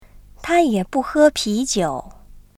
[Tā yě bù hē píjiǔ.]